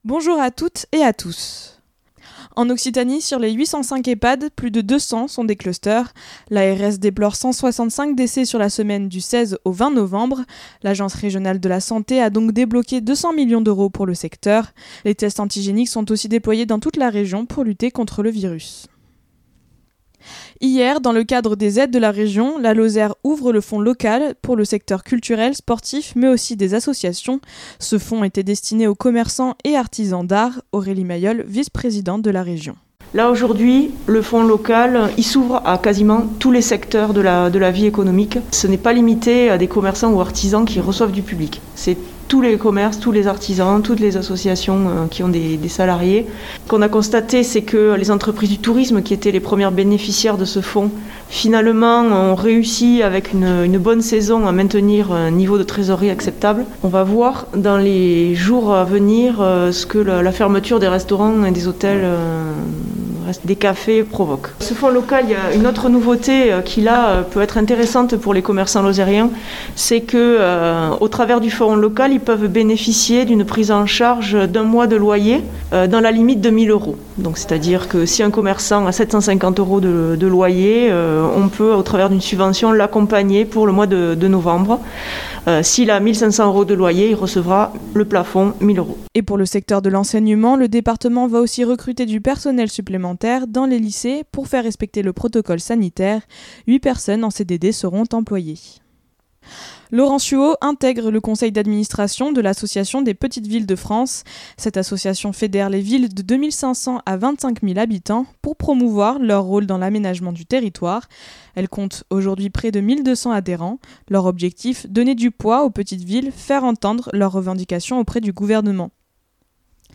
Les informations locales